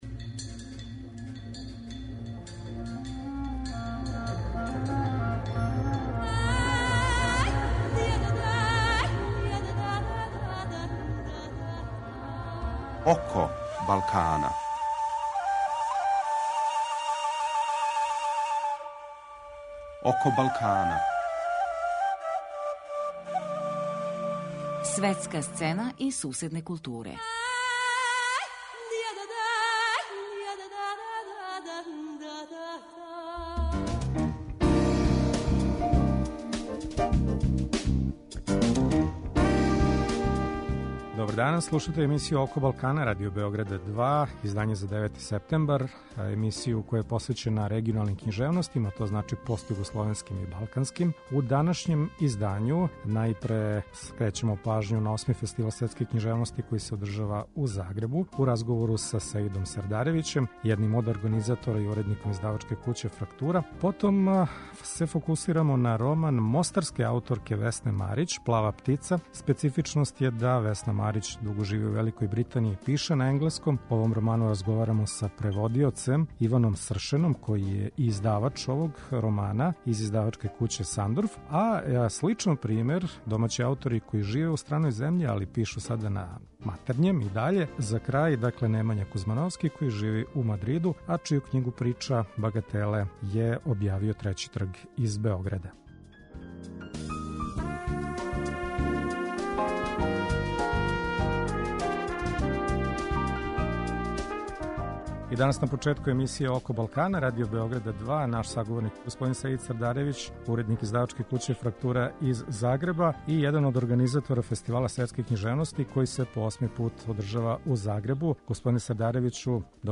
Eмисија је посвећена регионалним културама.